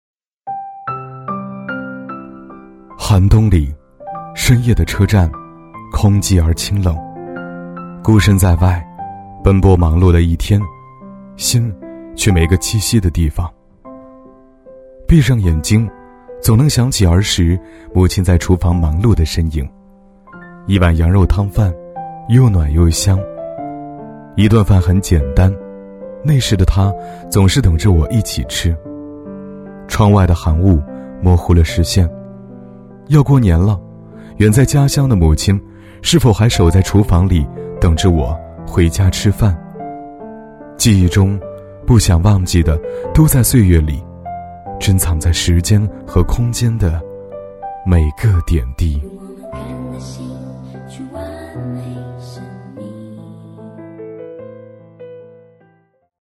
C男131号
【旁白】温情旁白样音
【旁白】温情旁白样音.mp3